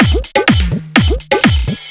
125 BPM Beat Loops Download